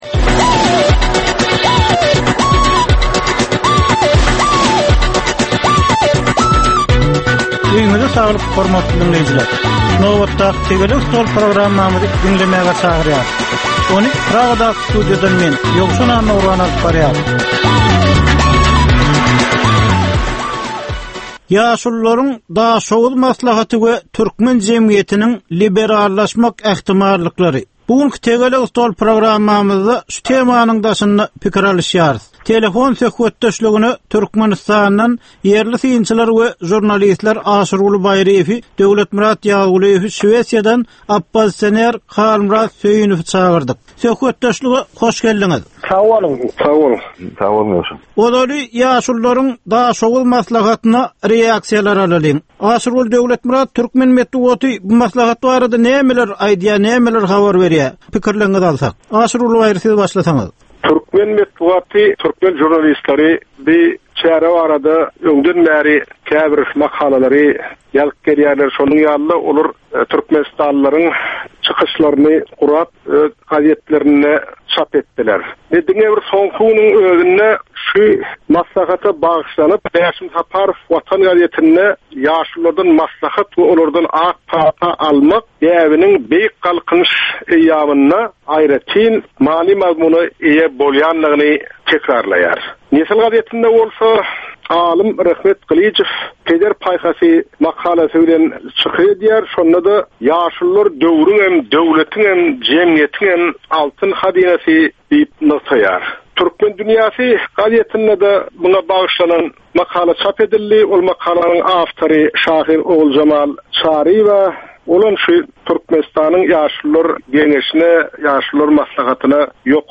Jemgyýetçilik durmuşynda bolan ýa-da bolup duran soňky möhum wakalara ýa-da problemalara bagyşlanylyp taýýarlanylýan ýörite diskussiýa. 30 minutlyk bu gepleşikde syýasatçylar, analitikler we synçylar anyk meseleler boýunça öz garaýyşlaryny we tekliplerini orta atýarlar.